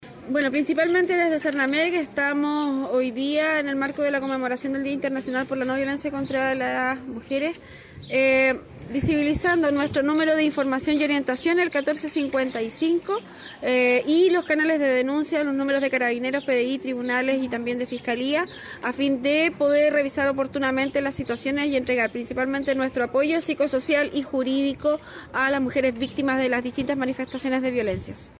Lissette Wackerling, directora regional del Servicio Nacional de la Mujer y la Equidad de Género, destacó el rol que juega la institución a la hora de orientar y apoyar a las victimas de este tipo de violencias.